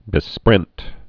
(bĭ-sprĕnt)